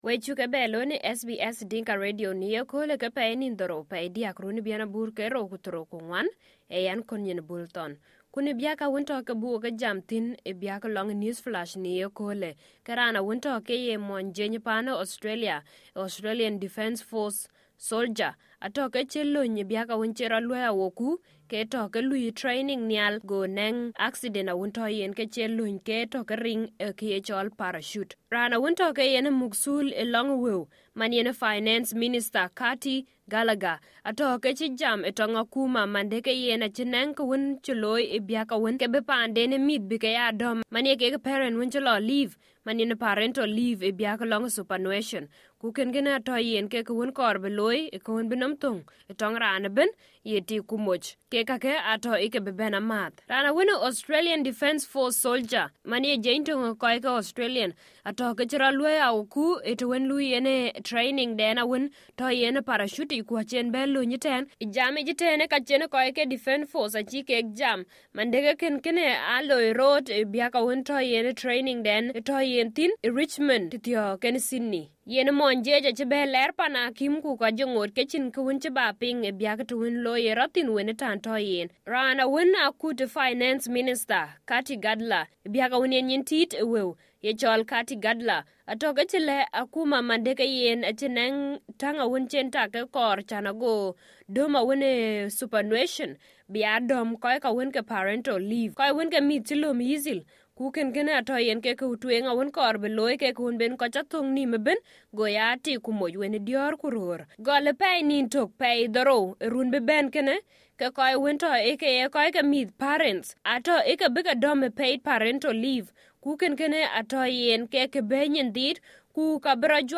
SBS Dinka News Flash 07/03/2024